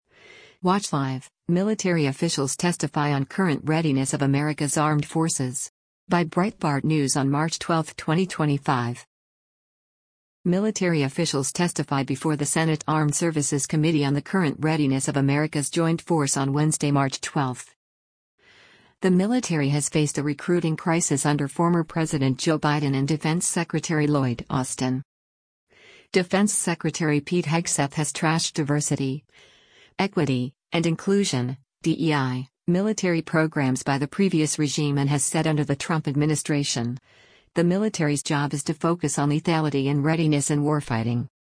Military officials testify before the Senate Armed Services Committee on the current readiness of America’s joint force on Wednesday, March 12.